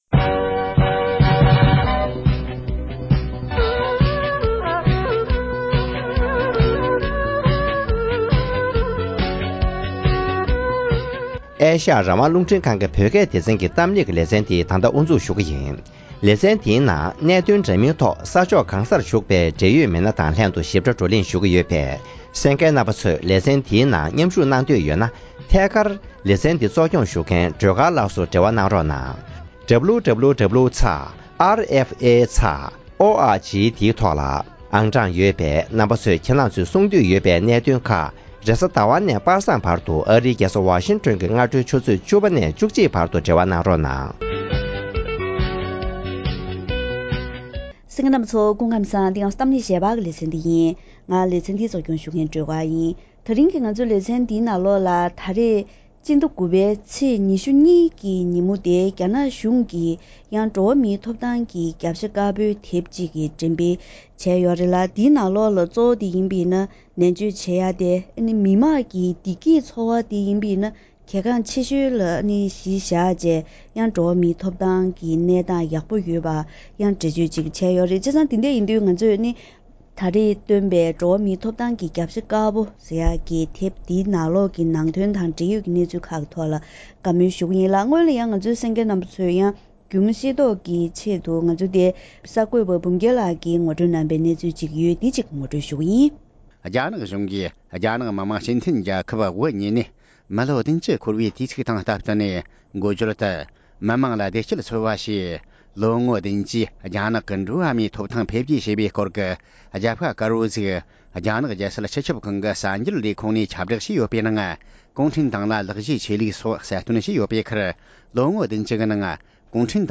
དེ་རིང་གི་གཏམ་གླེང་ཞལ་པར་ལེ་ཚན་ནང་